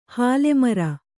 ♪ hāle mara